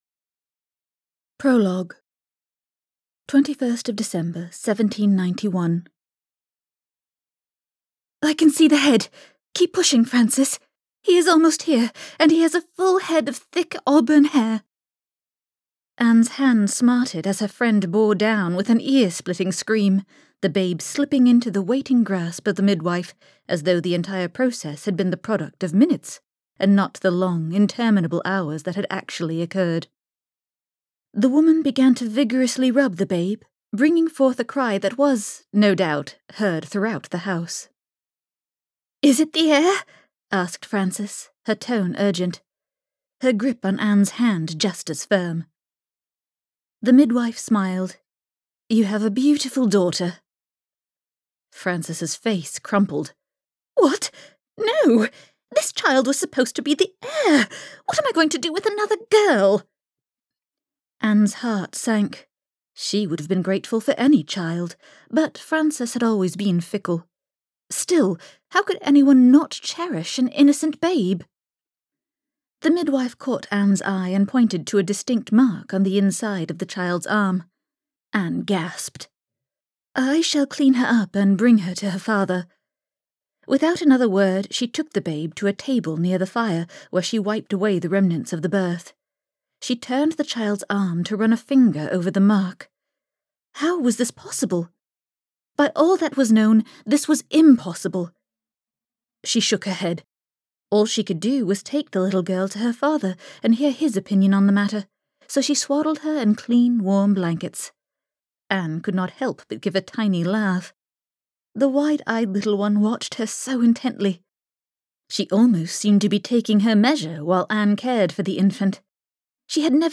Audiobook release party – The Unexpected Heir
Great narration!
Only the tinge of an American accent!